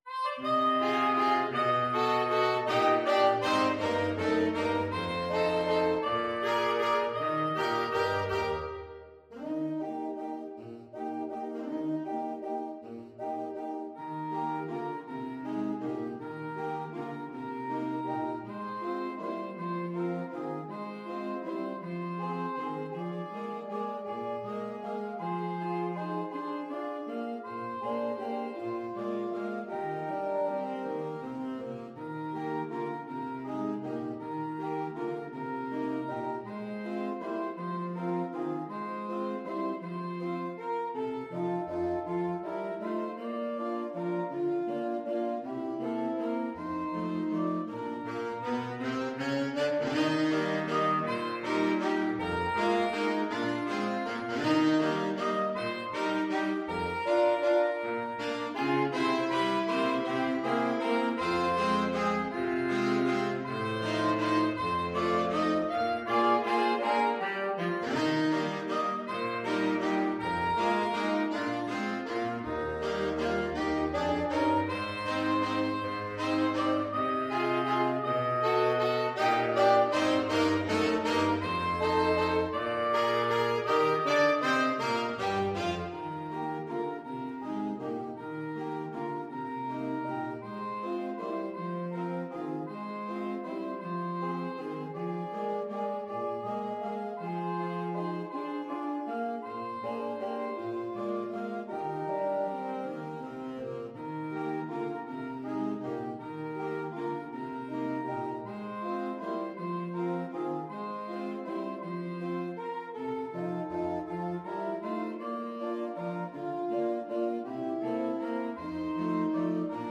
3/4 (View more 3/4 Music)
= 160 Tempo di valse = c.120
Pop (View more Pop Saxophone Quartet Music)